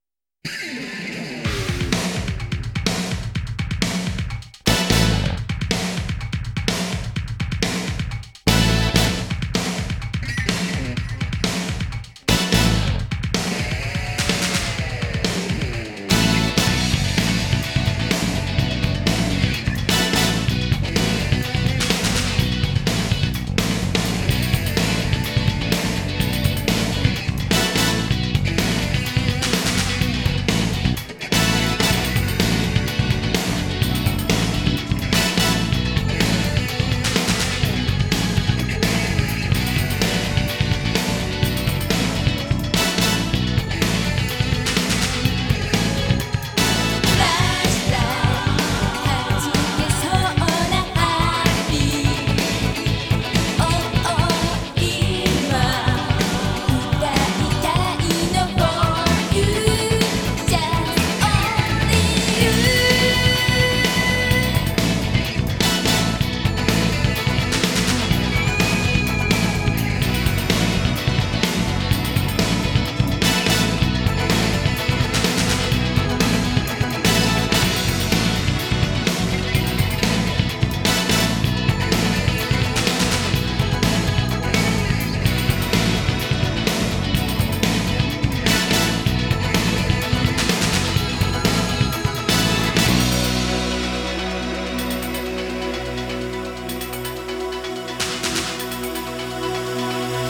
ジャンル(スタイル) JAPANESE POP / R&B